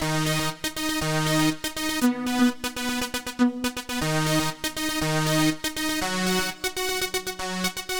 Power Pop Punk Keys 02a.wav